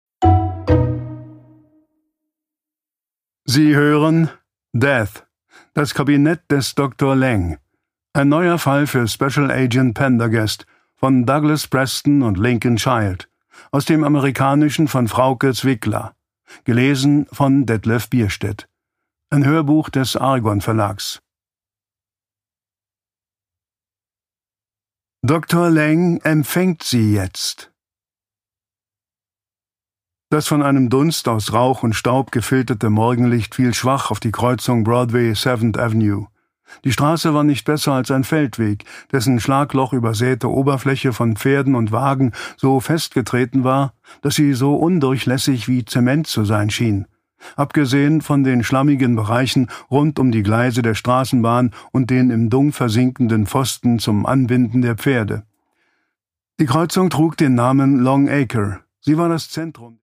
Produkttyp: Hörbuch-Download
Gelesen von: Detlef Bierstedt